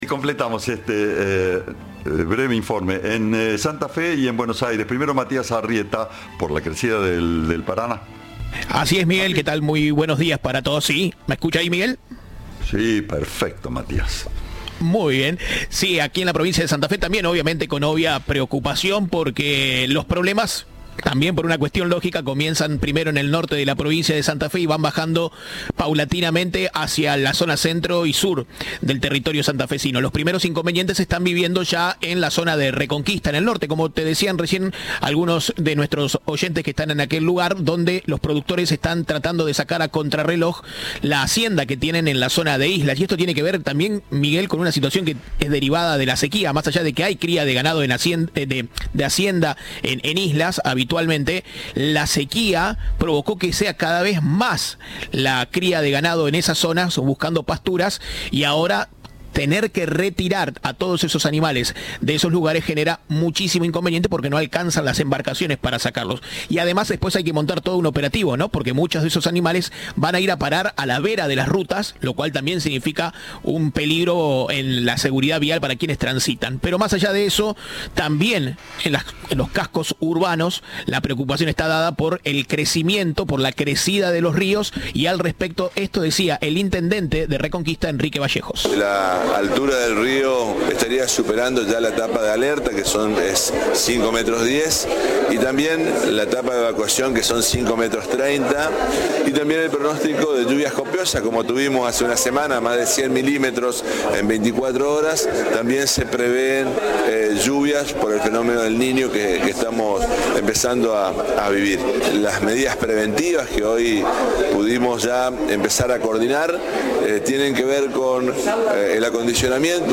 Enrique Vallejos, intendente de Reconquista, dijo que la altura del río estaría superando la etapa de alerta que son 5,10 metros y la etapa de evacuación de 5,30 metros.